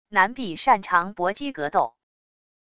男b――擅长搏击格斗wav音频